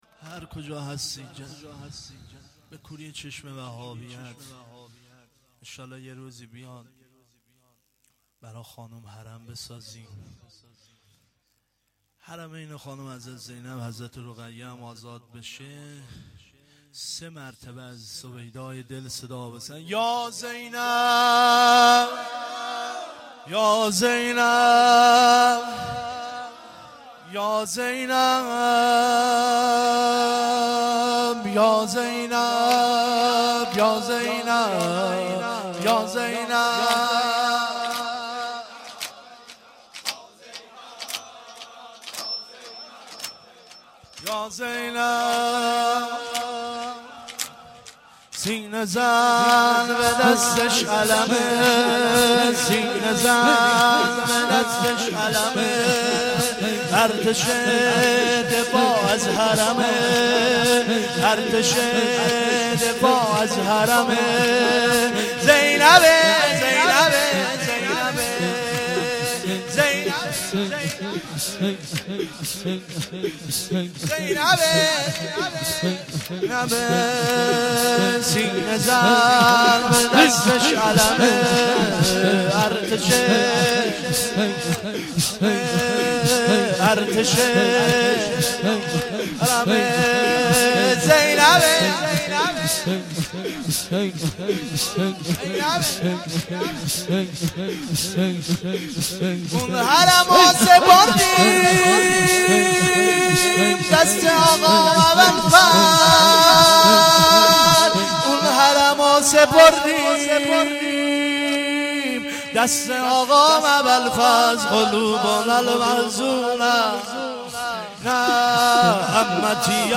• روضه العباس